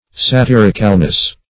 Sa*tir"ic*al*ness, n.